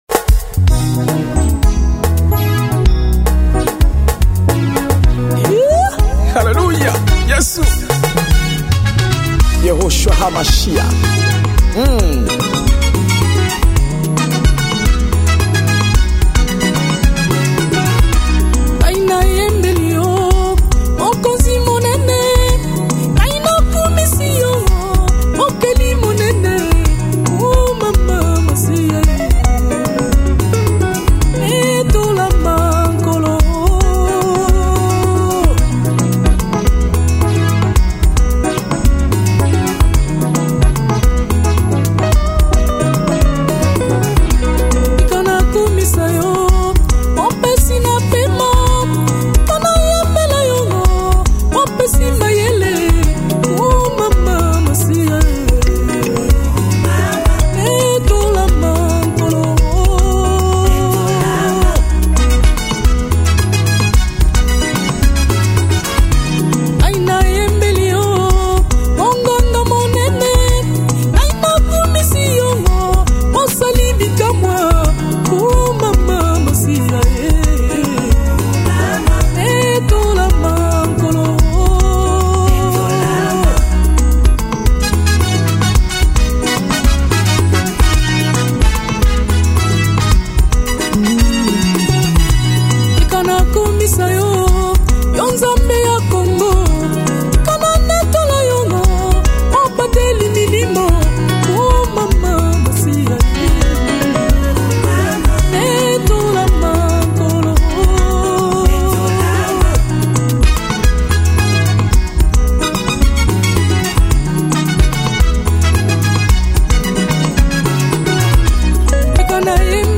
Gospel 2008